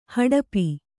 ♪ haḍapi